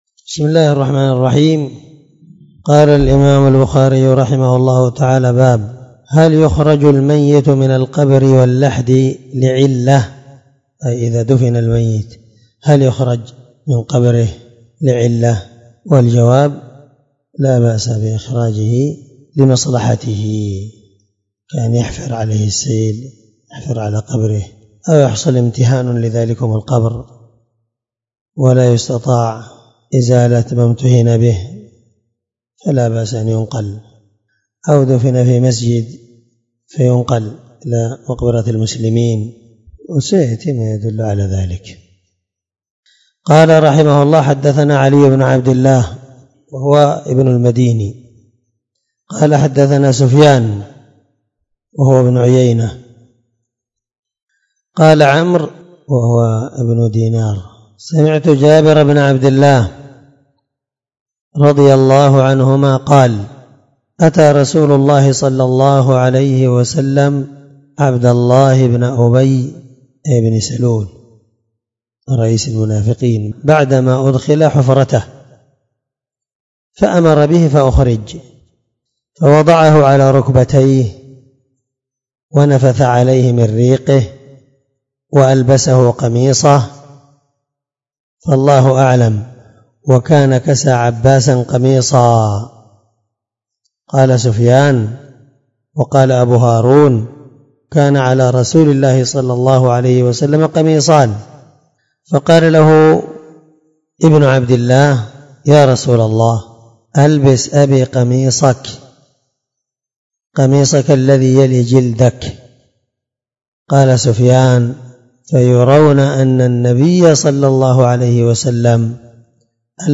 779الدرس 52من شرح كتاب الجنائز حديث رقم(1350-1352 )من صحيح البخاري